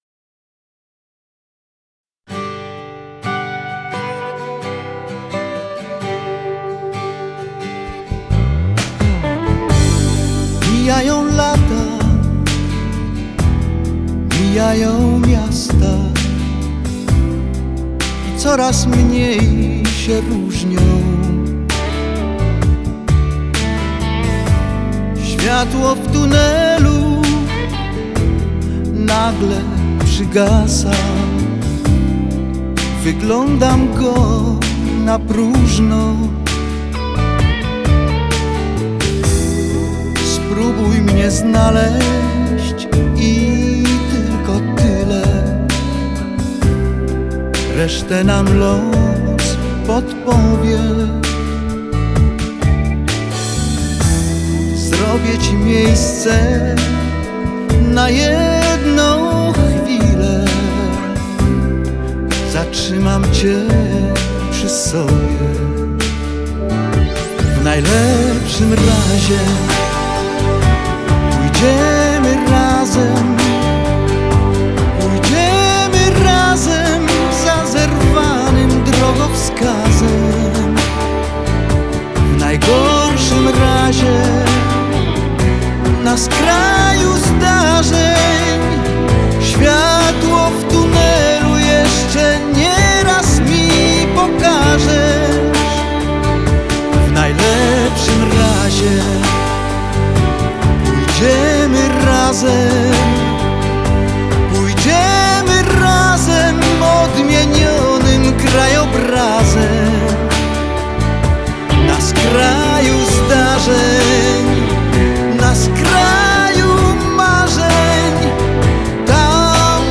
мелодичных композиций